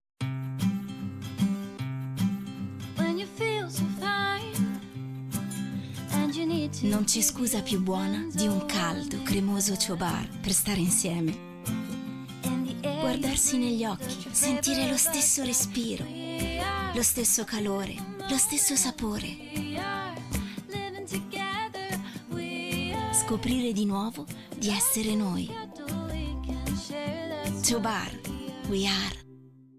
Spot Tv